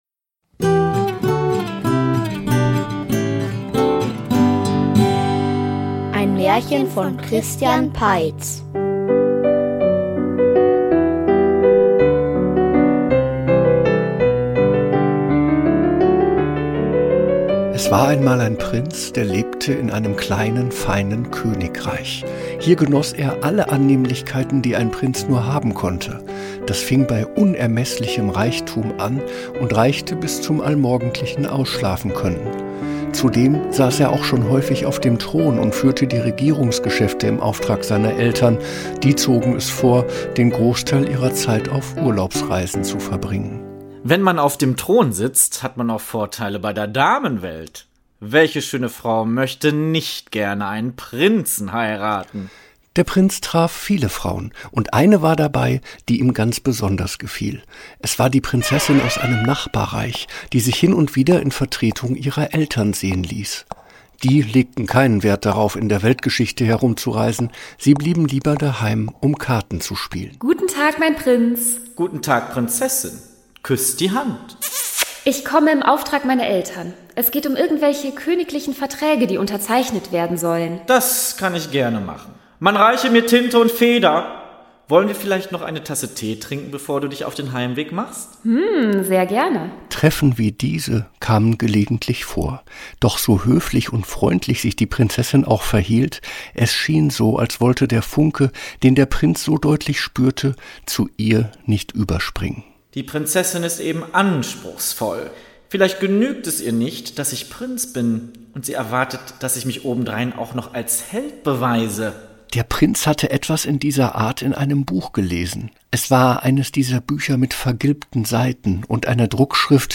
Wer auf dem Thron sitzt --- Märchenhörspiel #63 ~ Märchen-Hörspiele Podcast